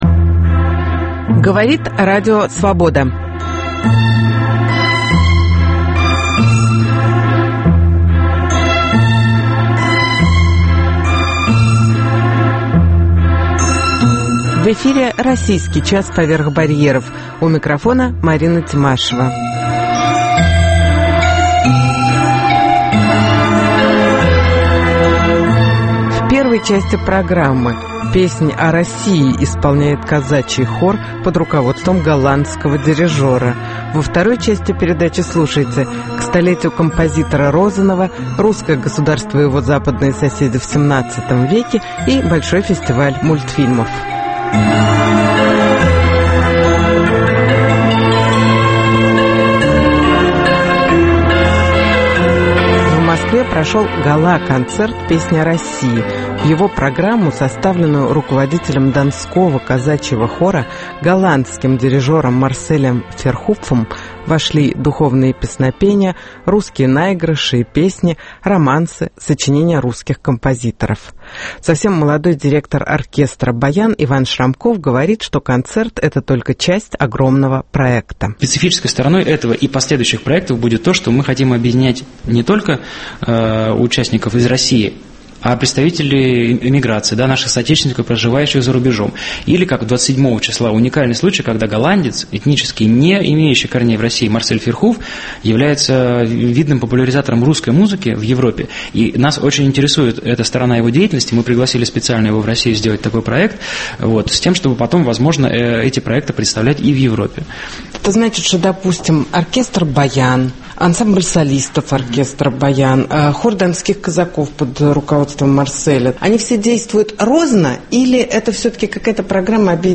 «Песнь о России» исполняет Хор Донских казаков под управлением голландского дирижера